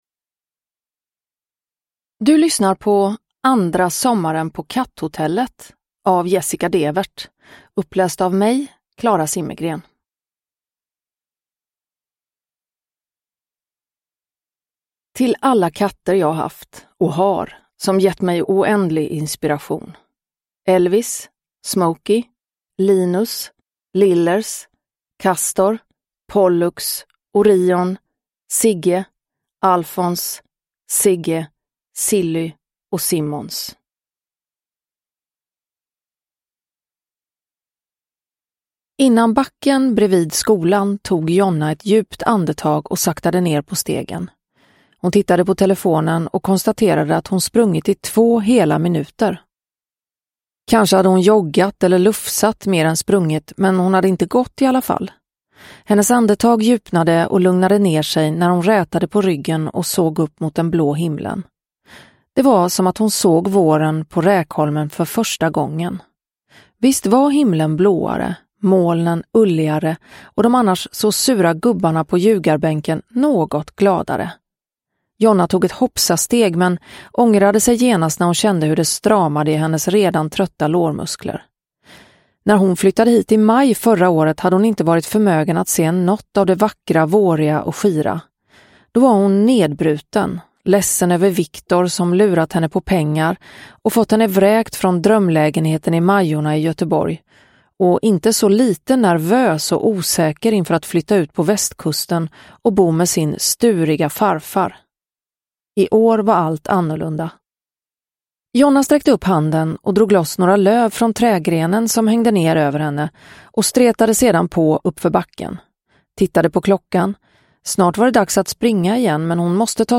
Andra sommaren på Katthotellet – Ljudbok – Laddas ner
Uppläsare: Klara Zimmergren